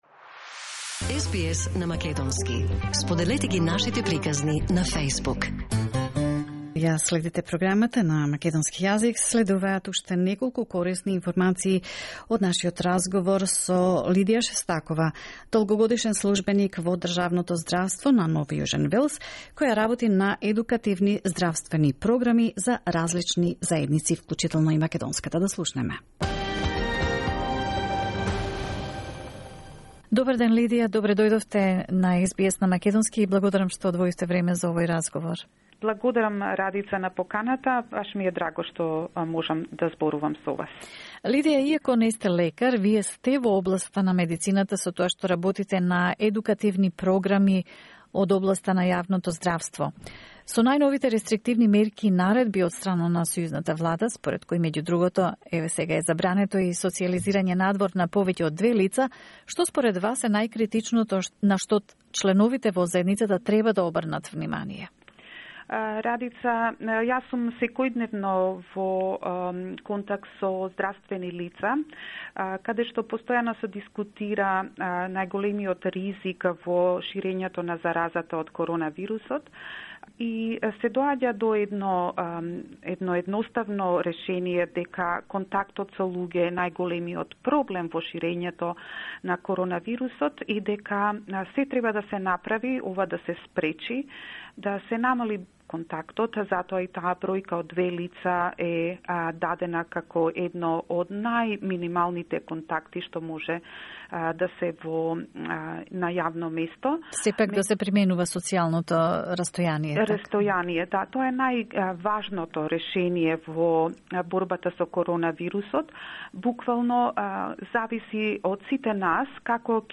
разговор